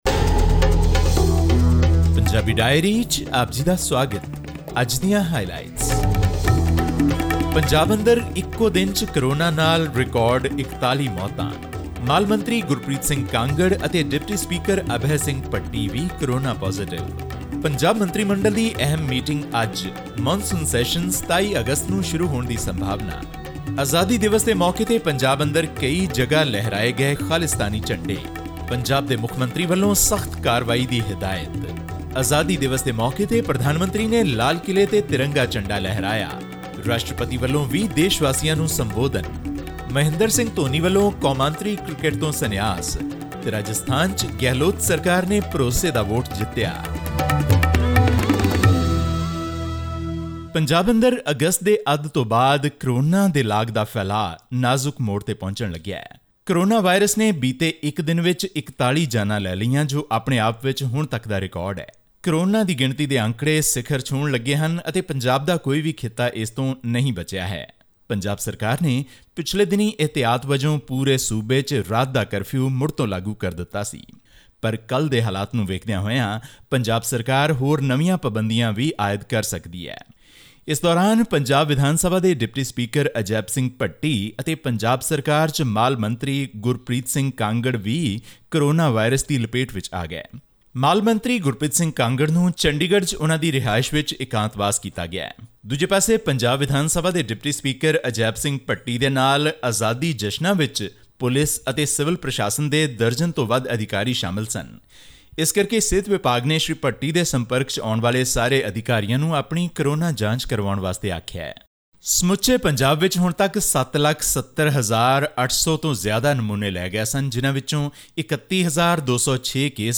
In our weekly news wrap from Punjab, this week, we bring you updates on the state's fight against the coronavirus, CM Capt. Amarinder Singh's direction for strict action against people who hoisted the Khalistan flag on August 15, and more.